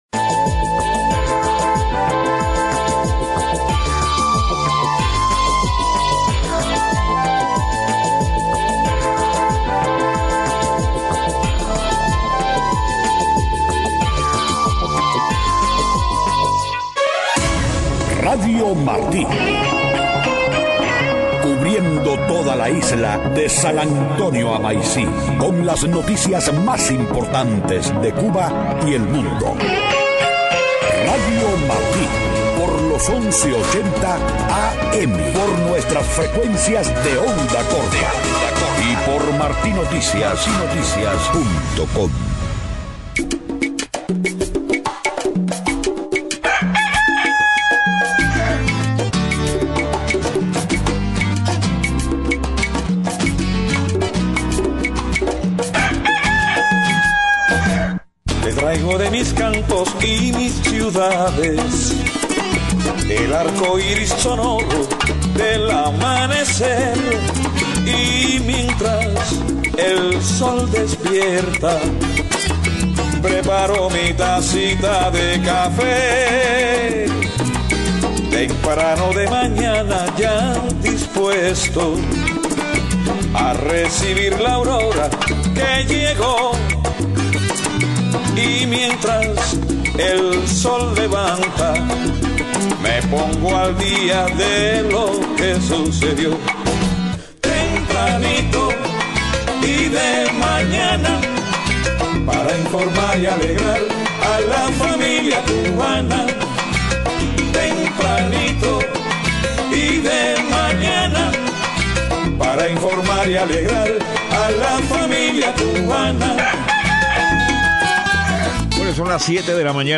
7:00 a.m Noticias: Expertos comentan rentabilidad de negocios de cuentapropistas en Cuba. Rechaza tribunal venezolano admitir pruebas de defensa del líder opositor Leopoldo López. Acusan en Colombia al líder de las FARC de buscar impunidad para crimines de guerra cometidos por la guerrilla.